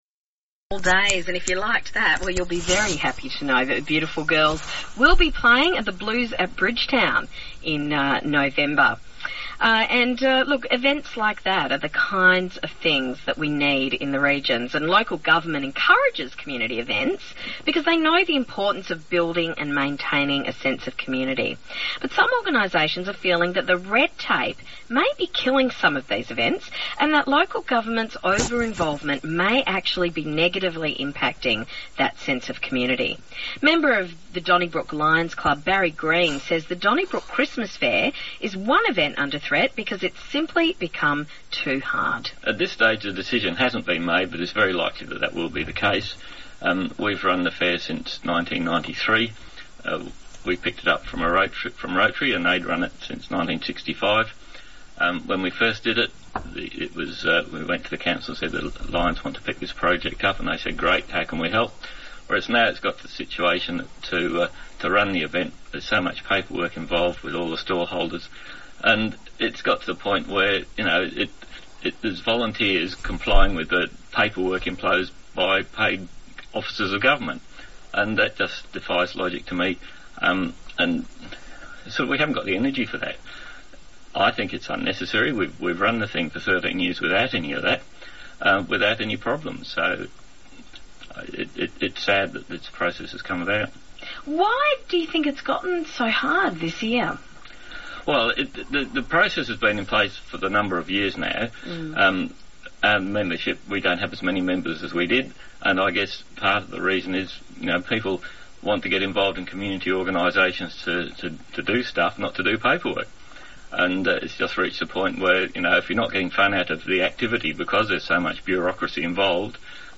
Interview on ABC Radio   Lions October 2014, questioning why governments get in the way of community organisations